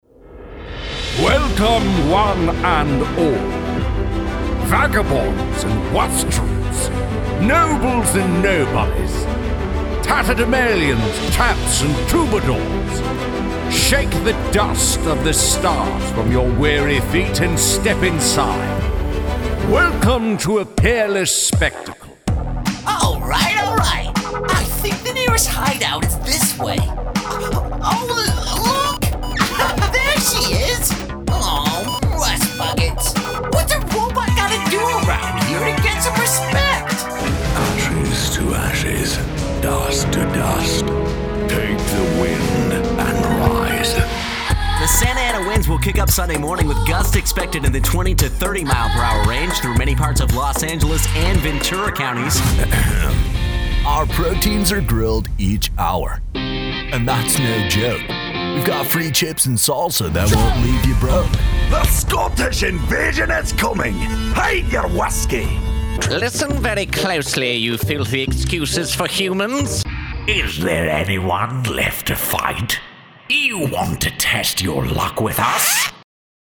Natürlich, Unverwechselbar, Zugänglich, Vielseitig, Warm
Persönlichkeiten